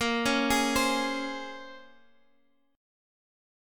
A#m9 chord